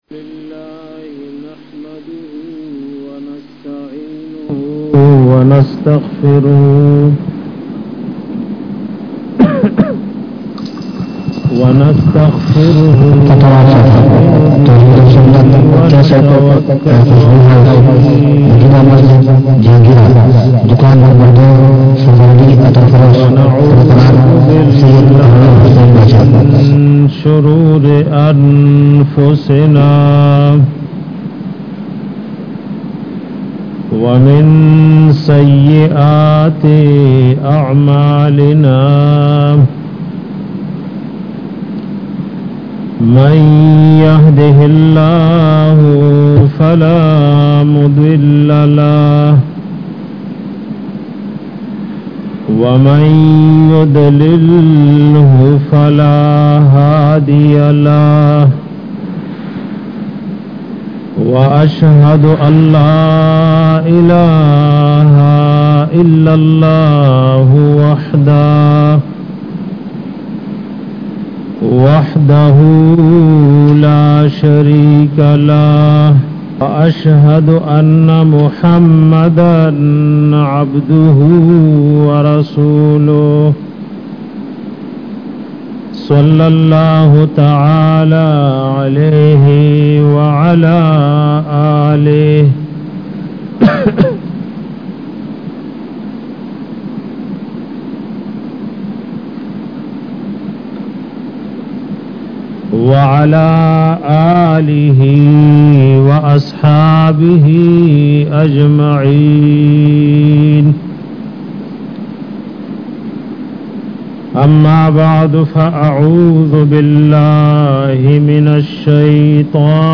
bayan benimazi ka anjam by al shiekh part 2